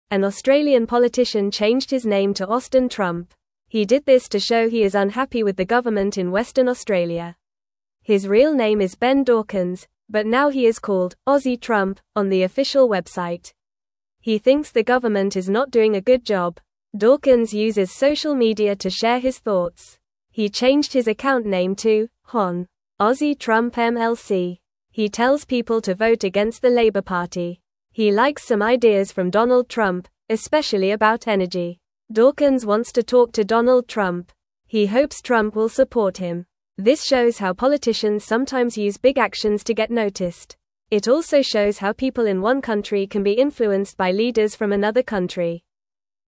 Fast
English-Newsroom-Lower-Intermediate-FAST-Reading-Man-Changes-Name-to-Austin-Trump-for-Attention.mp3